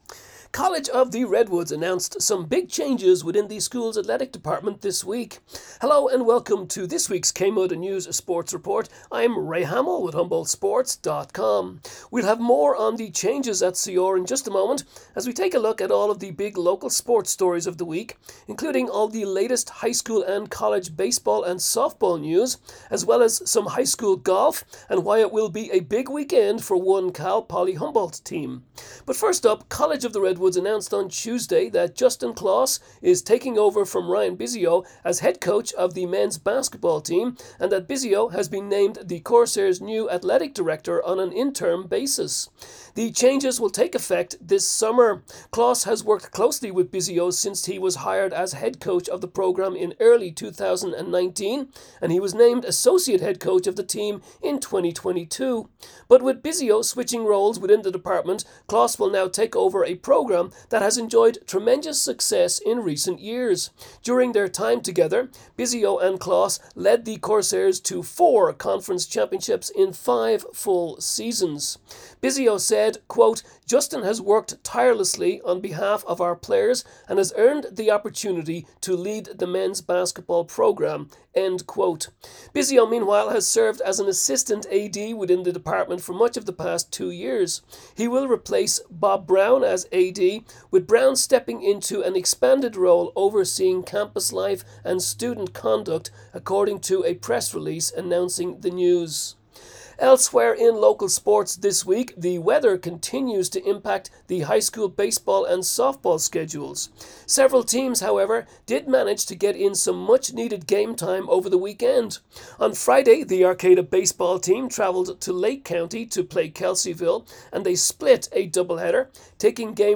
KMUD News Sports Report March 27